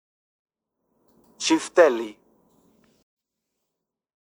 Audio file of the word "Çifteli"
Sound-of-the-word--ifteli-.mp3